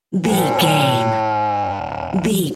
Dinosaur baby growl little creature
Sound Effects
scary
ominous
eerie